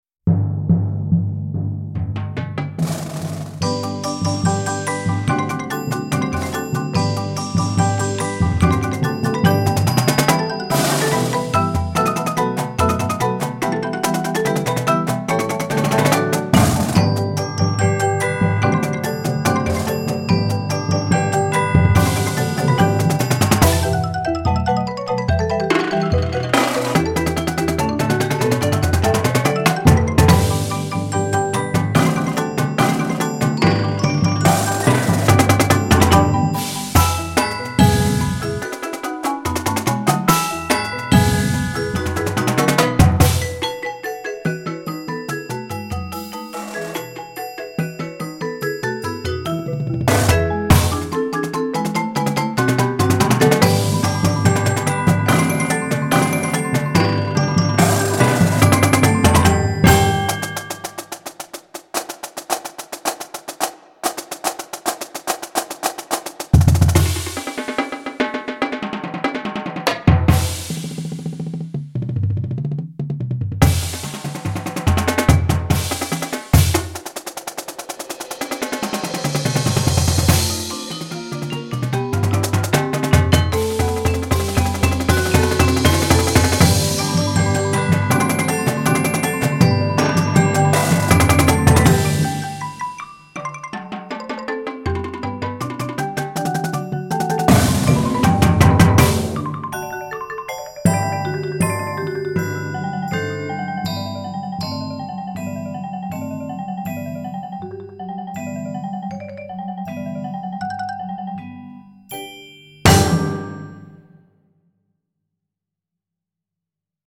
Medium-Advanced Marching Feature